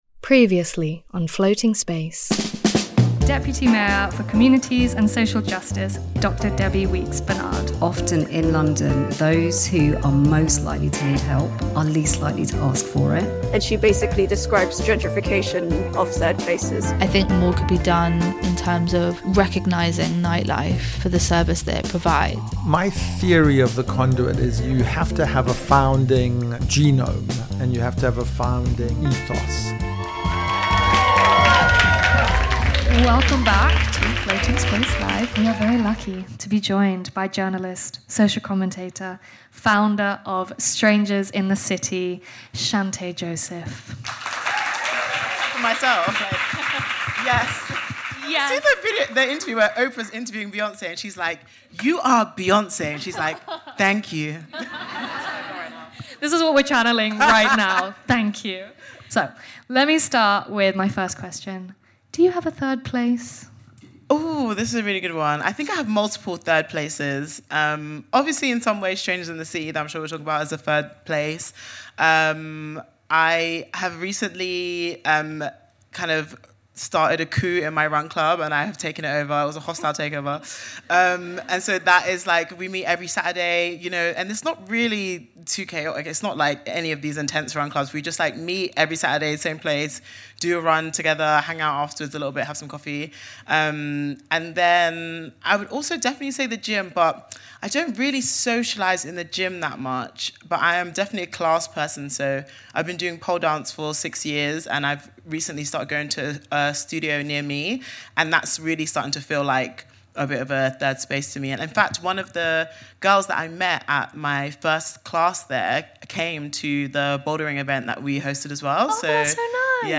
Welcome back to part two of Floating Space: Live at the Roundhouse!